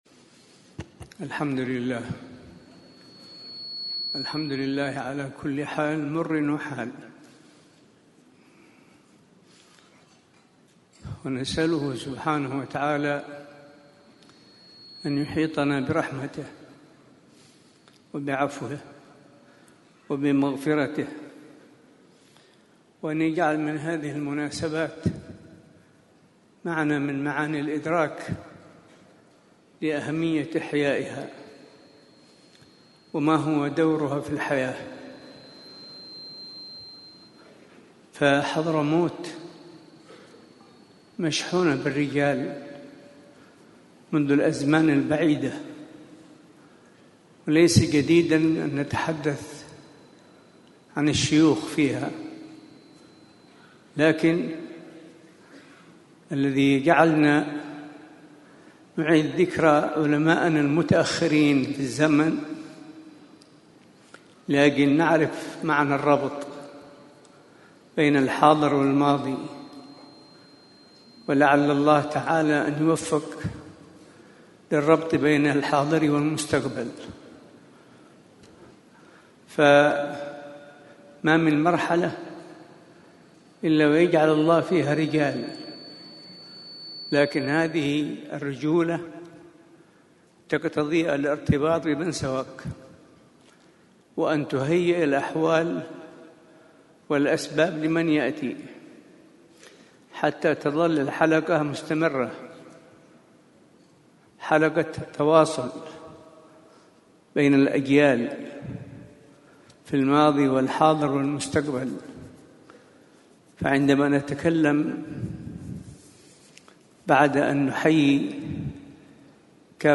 بالقاعة الكبرى برباط الإمام المهاجر
محاضرة